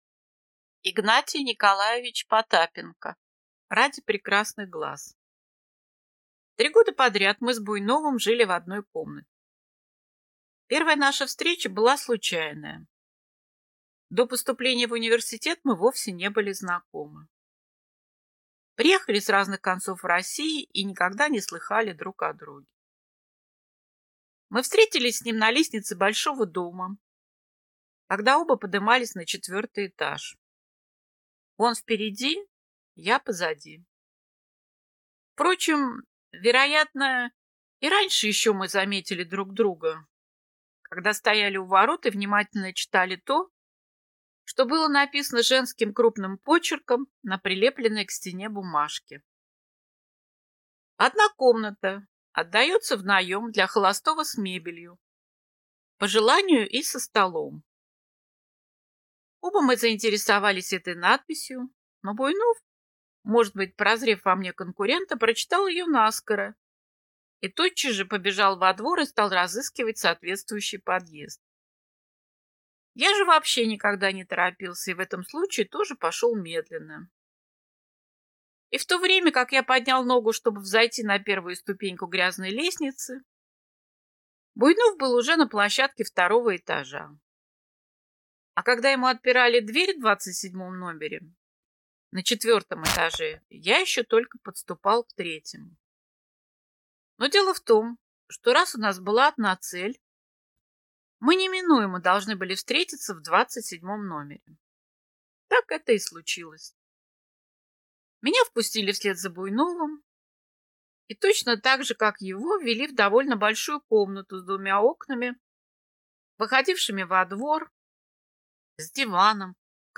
Аудиокнига Ради прекрасных глаз | Библиотека аудиокниг